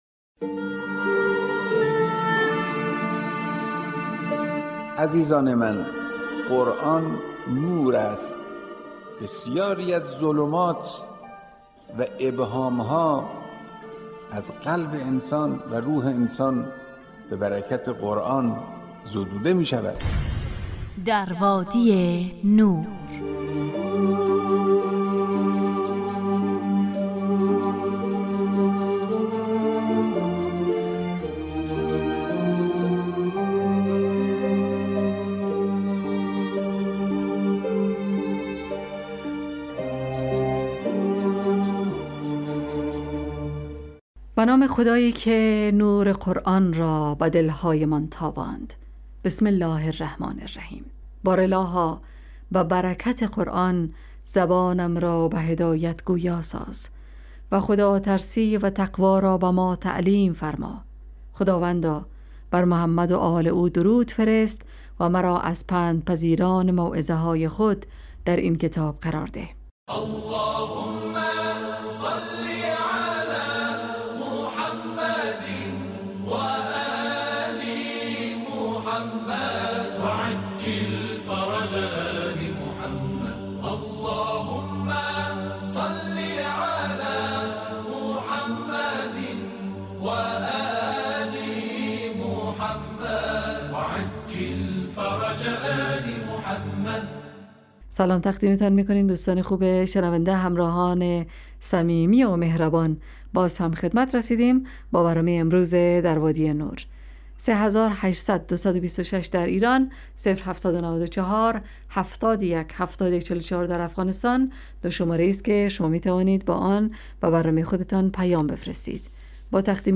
در وادی نور برنامه ای 45 دقیقه ای با موضوعات قرآنی روزهای فرد: ( قرآن و عترت،طلایه داران تلاوت ، دانستنیهای قرآنی، ایستگاه تلاوت، تفسیر روان و آموزه های...